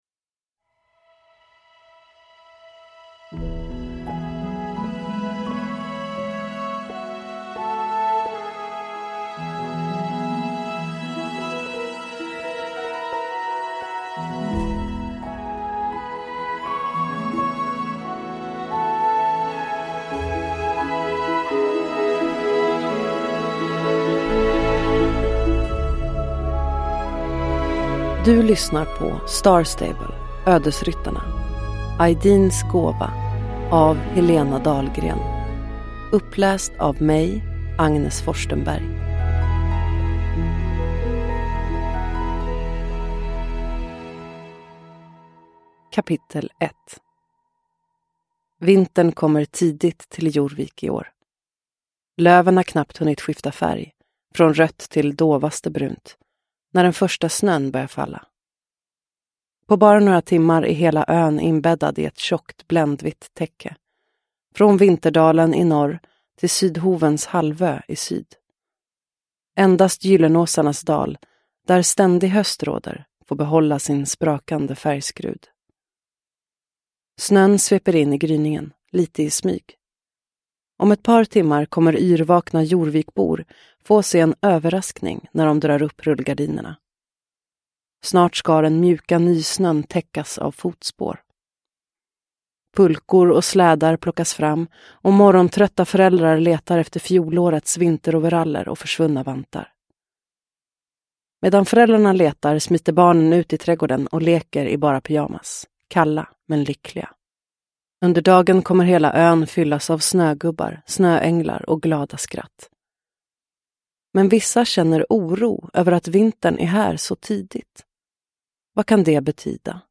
Ödesryttarna. Aideens gåva – Ljudbok